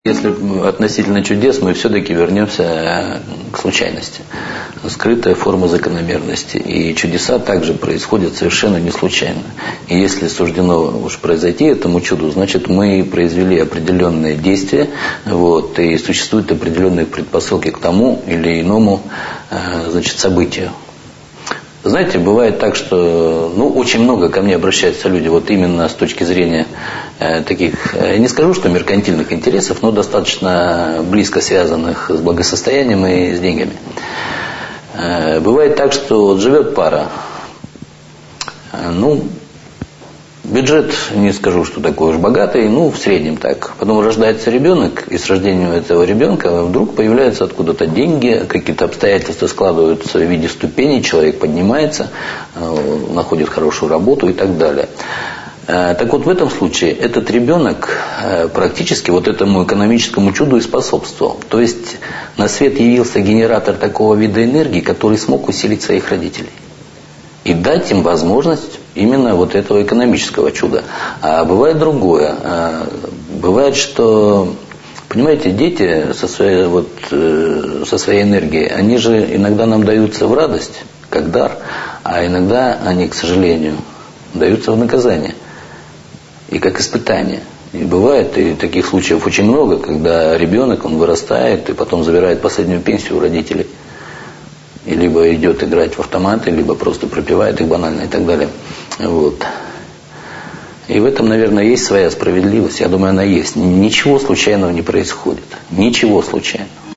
Аудиокнига: Экстрасенсы